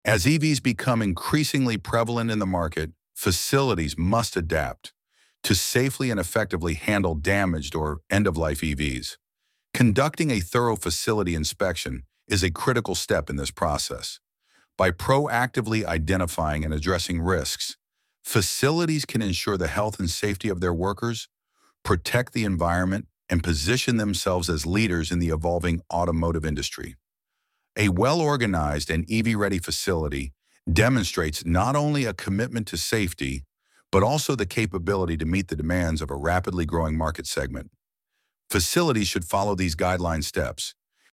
ElevenLabs_Topic_3.1.1.mp3